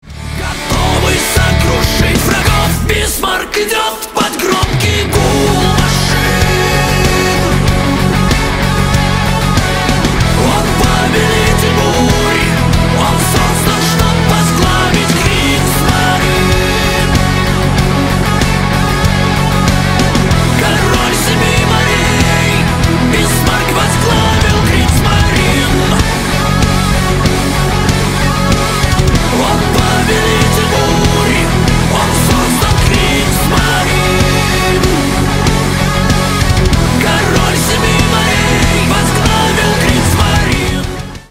громкие
металл